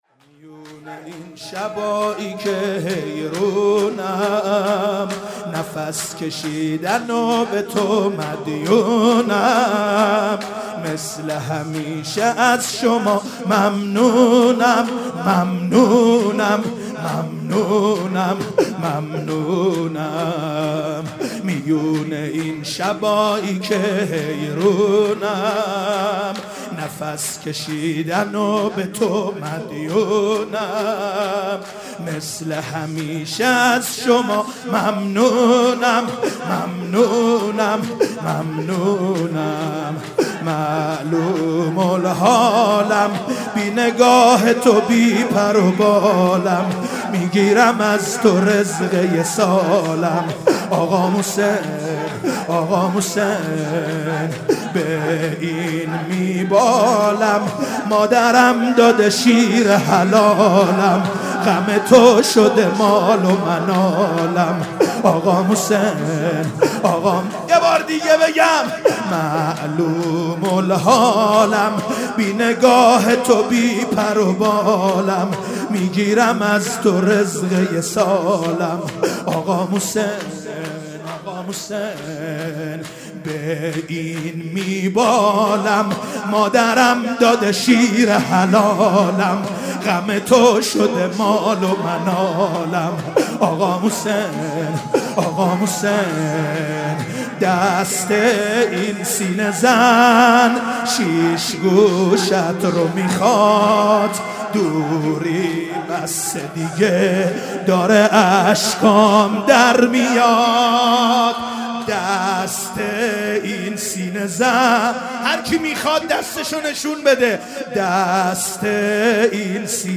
شهادت امام سجاد (ع)